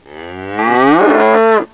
kuh.wav